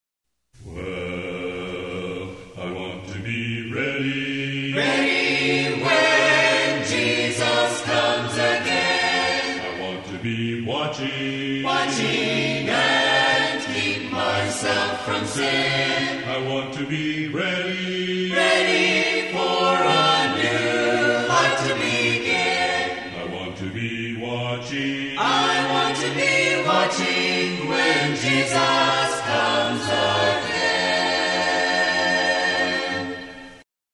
Soprano
Altos
Tenor
Bass